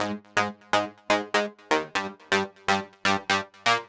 Terror Mono Ab 123.wav